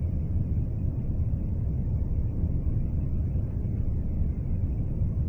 spaceship_engine_loop.wav